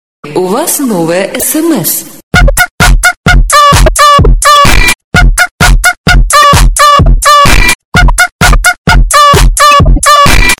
Дикая музыка для смс